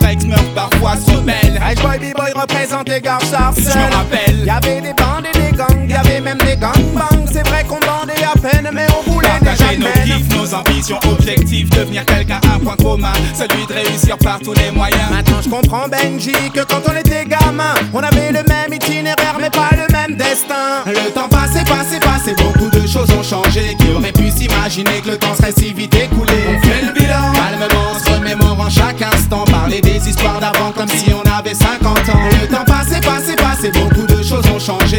Off-beat гитары и расслабленный ритм
Reggae French Pop
Жанр: Поп музыка / Регги